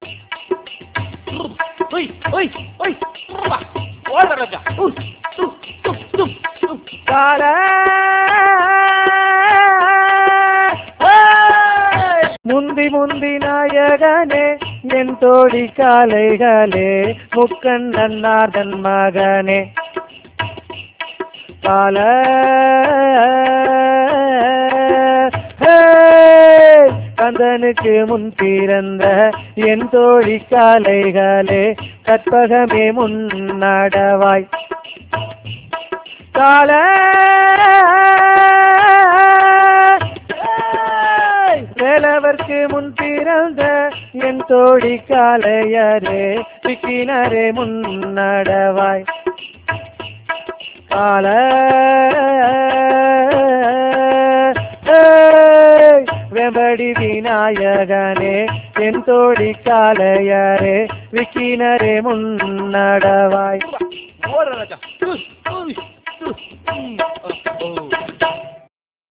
3.4.1 வேளாண்மைத் தொழிற்பாடல்கள்
ஏர் ஓட்டும்போது பாடல் பாடும் பழக்கம் உண்டு.
காளைகளை எந்தோழிக் காளைகளே, எந்தோழனார் காளைகளே என்று விளித்துப் பாடல்கள் பாடுகின்றனர்.